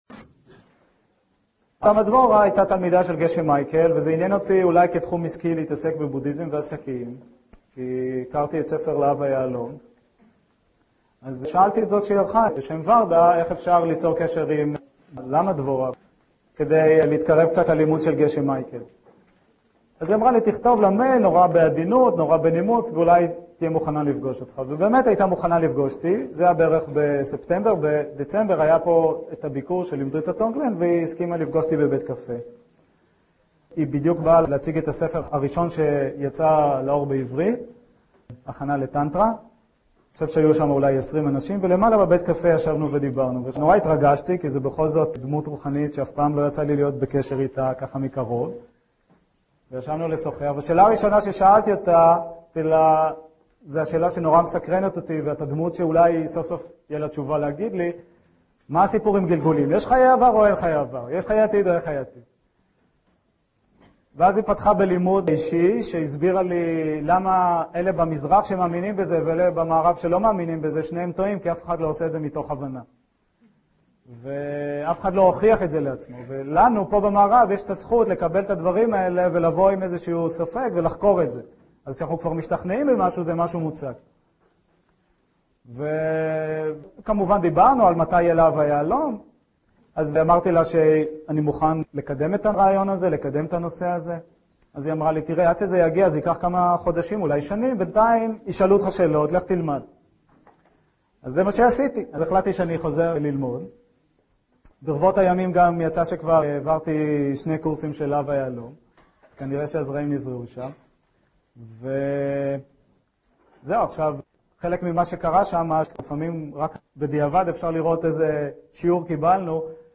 סדנת מדיטציה: לו ג'ונג – אימון התודעה 4 - שיטה חכמה